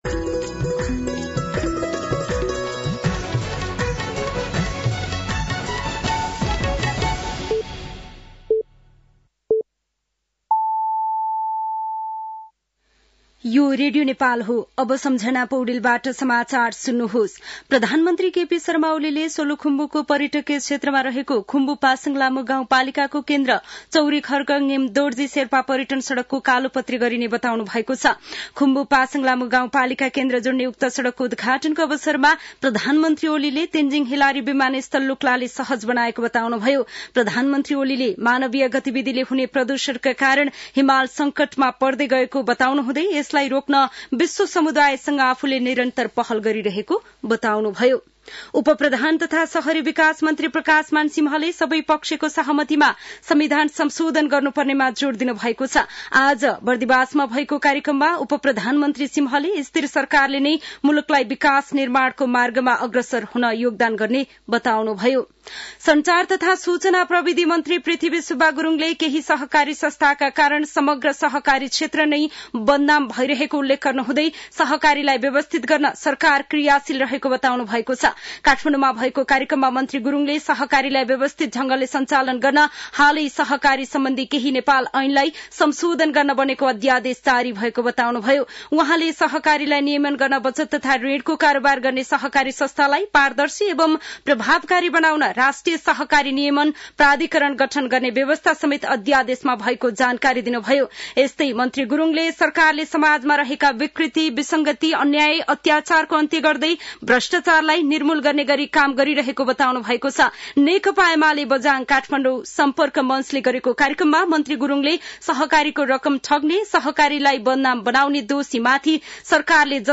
साँझ ५ बजेको नेपाली समाचार : २१ पुष , २०८१
5-PM-Nepali-News-9-20.mp3